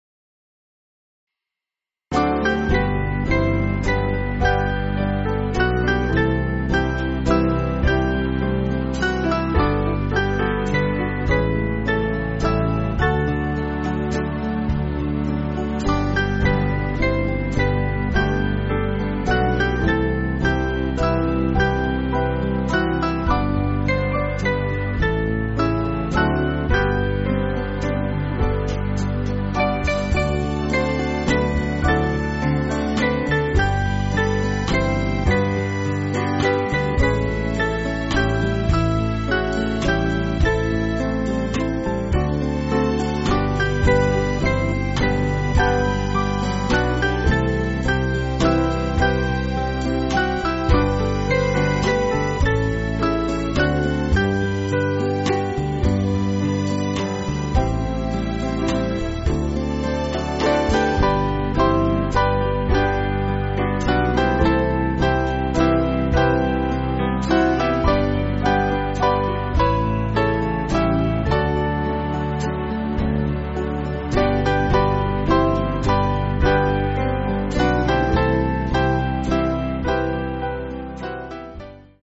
Small Band
(CM)   3/G